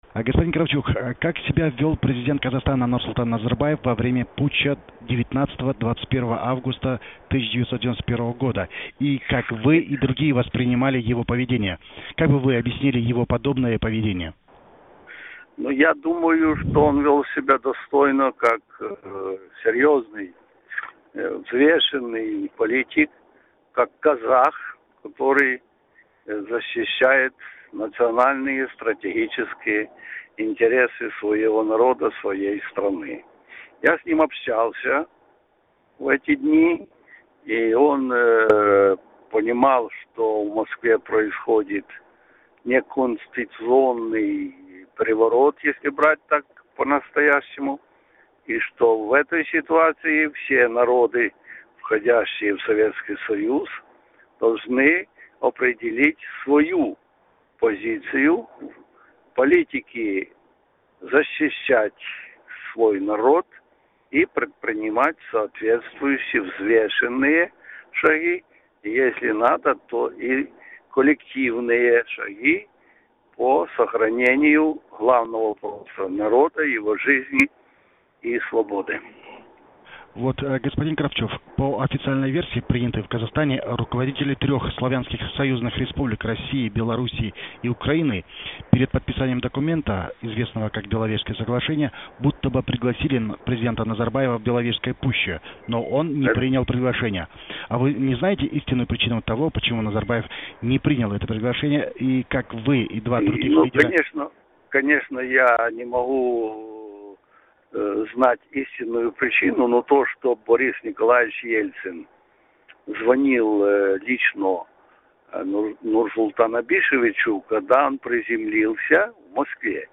Украинаның бұрынғы президенті Леонид Кравчук Азаттық радиосына сұқбат берді. Ол ГКЧП бүлігі кезінде, Совет одағы құлаған тұста Нұрсұлтан Назарбаевтың іс-әрекеті қандай болғанын айтады.
Леонид Кравчуктың сұқбаты.